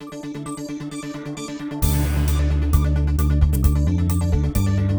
disco.wav